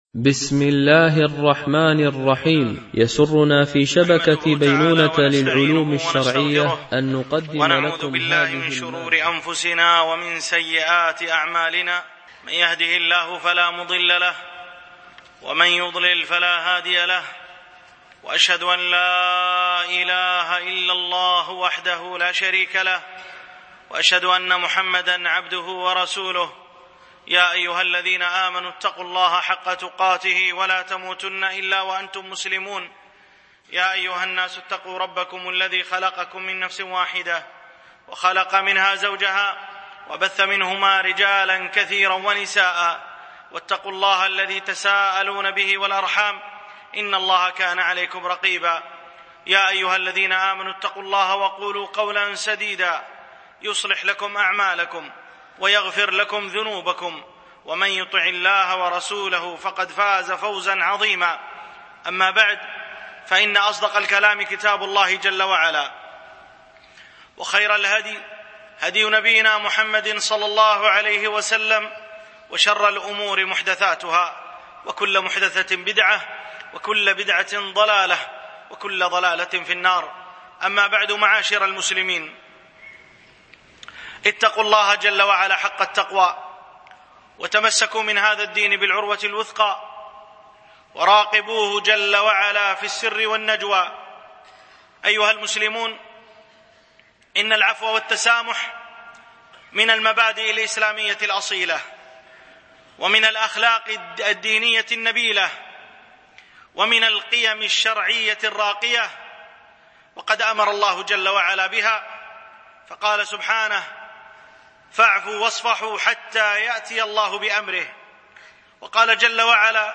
العفو والتسامح الألبوم: دروس مسجد عائشة (برعاية مركز رياض الصالحين ـ بدبي) المدة